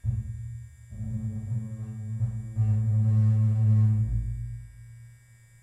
描述：样品，打击乐器，鼓，丙烷罐，金属，金属，响，铿锵声
Tag: 逐步 声音 100 20 坦克 撞击 产生 烘干机 音调 混响 顶部系列 丙烷 品种繁多 振铃 命中